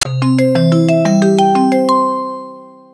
call.wav